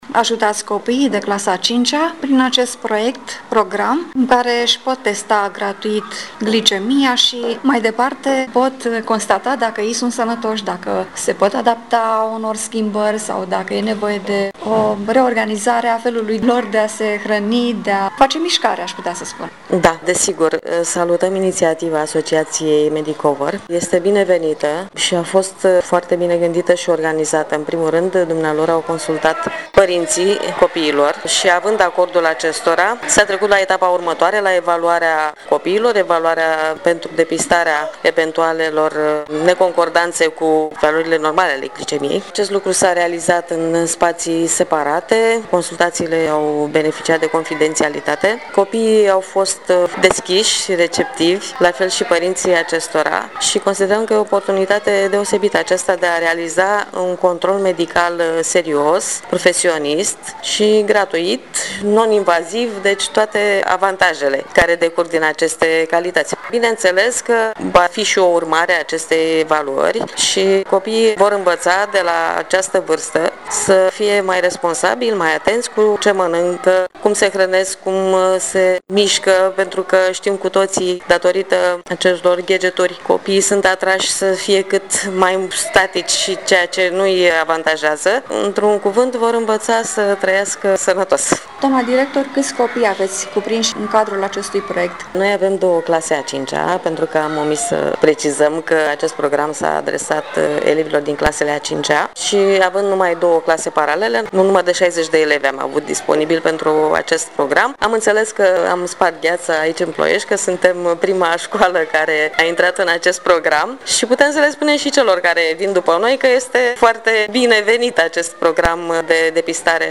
Reportaje și interviuri radio difuzate la Radio SOS Prahova, în data de 14 noiembrie 2017, cu ocazia Zilei Mondiale a Diabetului.
Interviu